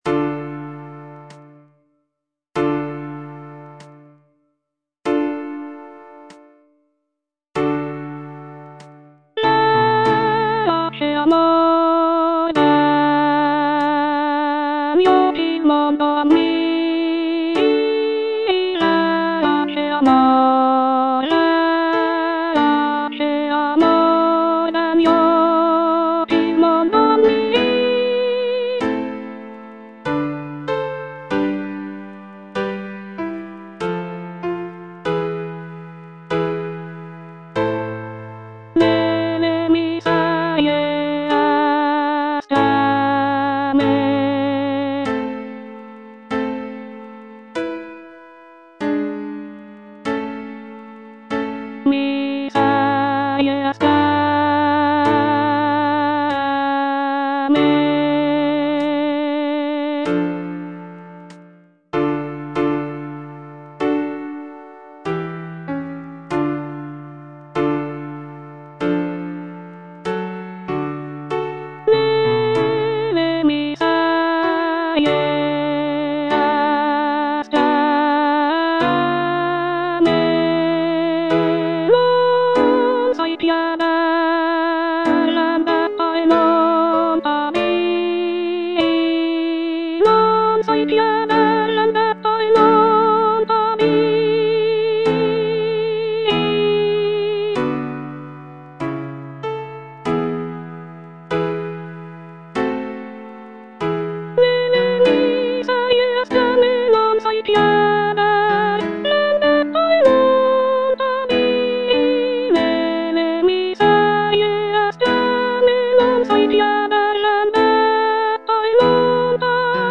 C. MONTEVERDI - LAMENTO D'ARIANNA (VERSION 2) Coro IV: Verace amor (soprano II) (Voice with metronome) Ads stop: auto-stop
The piece is based on the character of Ariadne from Greek mythology, who is abandoned by her lover Theseus on the island of Naxos. The music is characterized by its expressive melodies and poignant harmonies, making it a powerful and moving example of early Baroque vocal music.